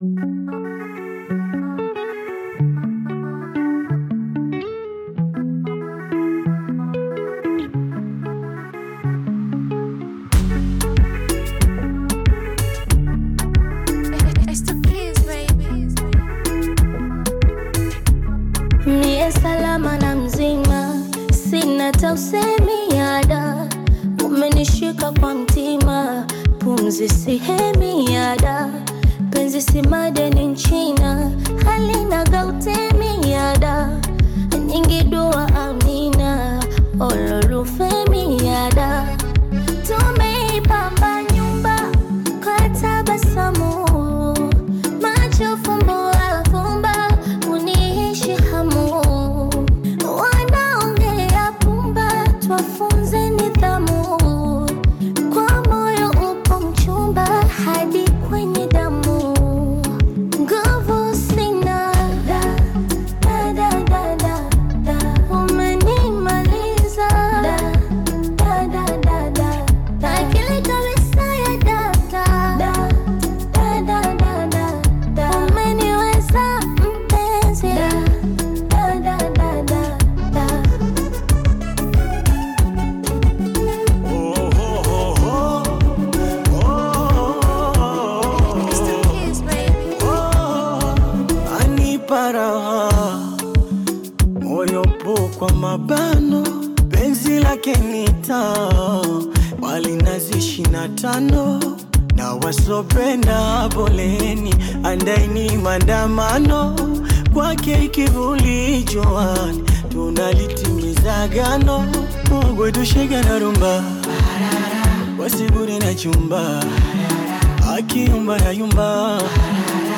creating a lively and infectious composition.